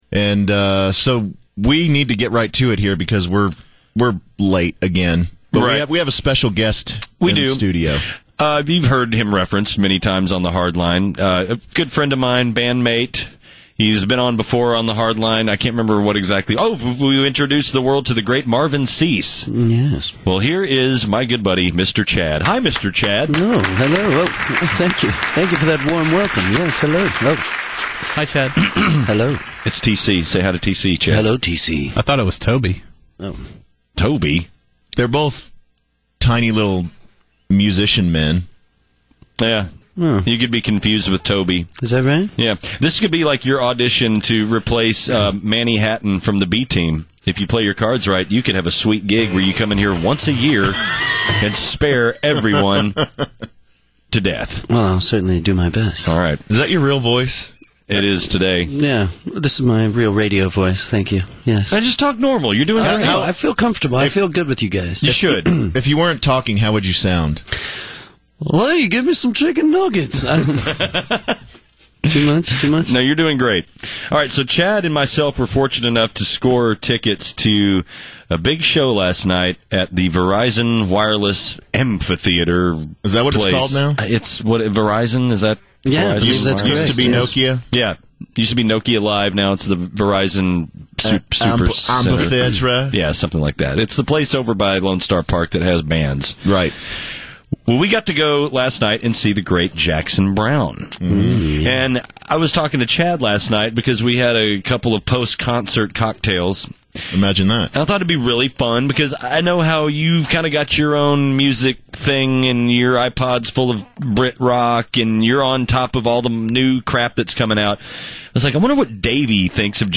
He stopped by the studio to discuss a Jackson Brown concert and help bolster the ratings of the struggling-yet-lovable Orphanage.